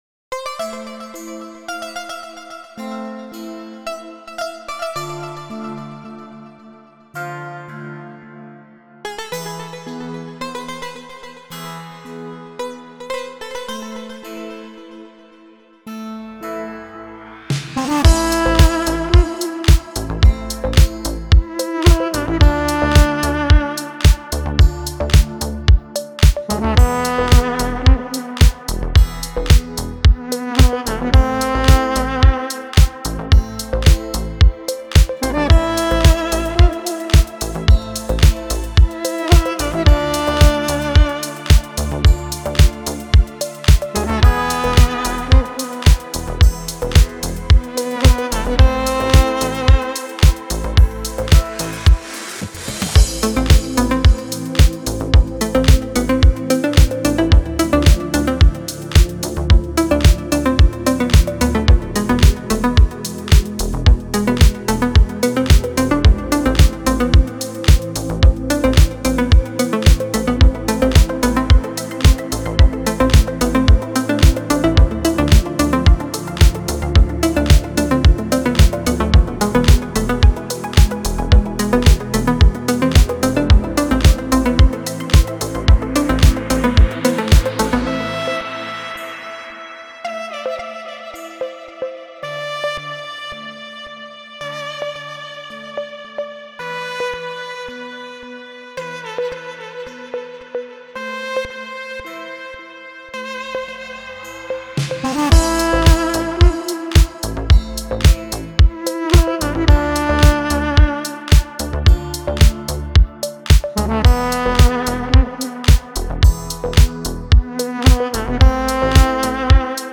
موسیقی بی کلام دیپ هاوس ریتمیک آرام